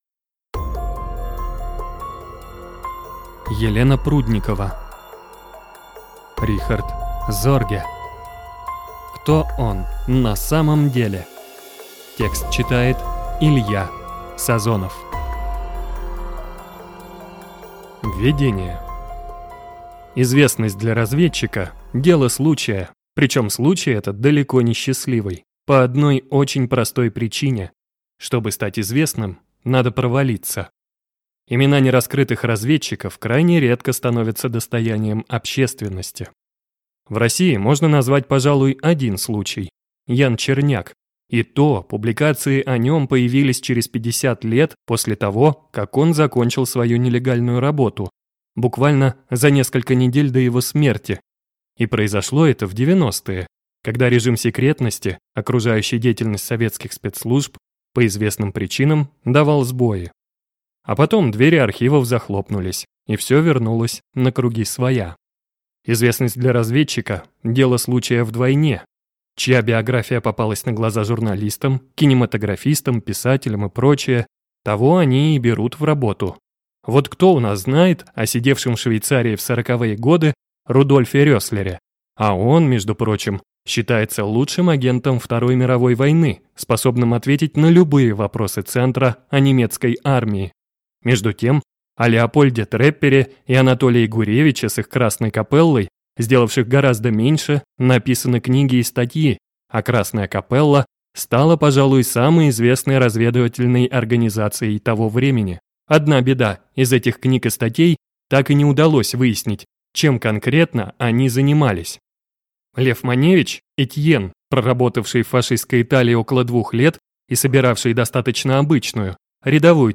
Aудиокнига Рихард Зорге. Кто он на самом деле?